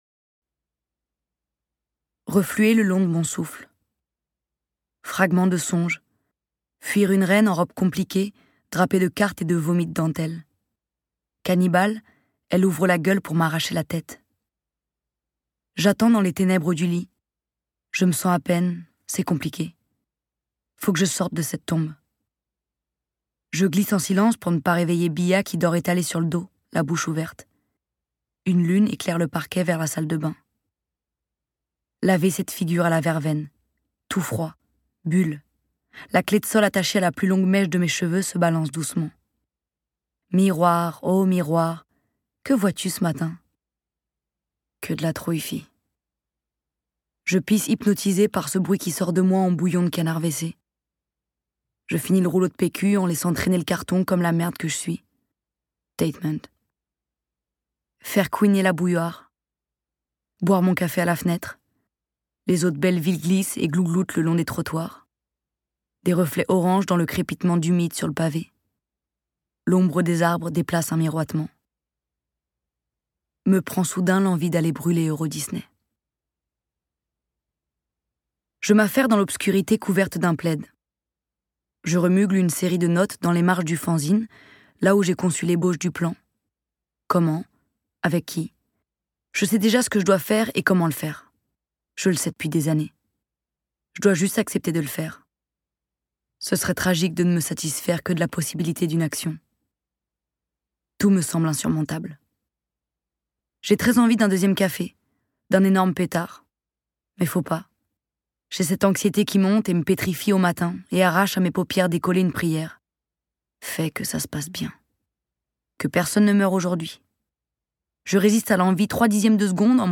Get £2.55 by recommending this book 🛈 De Belleville à Marne-la-Vallée, une bande d'enfants part en croisade pour mettre le feu à Eurodisney. Bienvenue aux avants-postes de la Révolution, menée par la lecture engagée, poétique et militante d'Aloïse Sauvage.